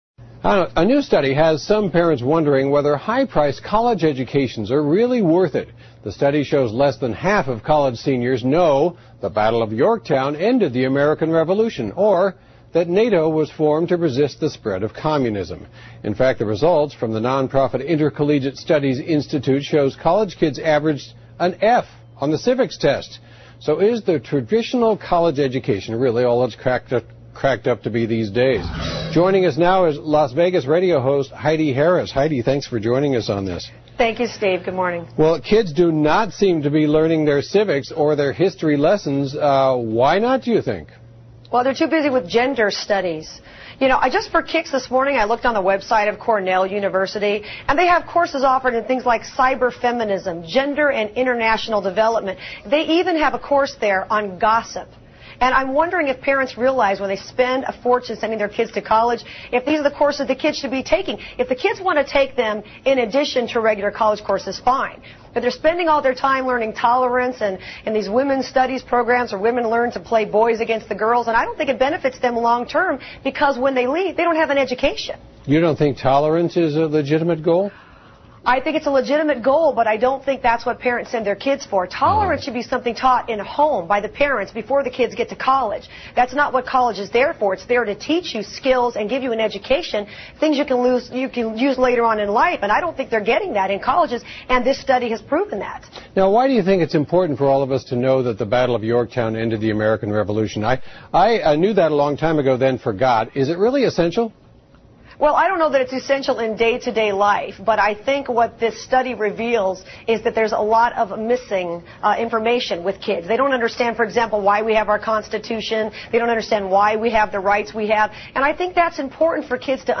访谈录 【Interview】2007-09-26&09-28, 大学学什么? 听力文件下载—在线英语听力室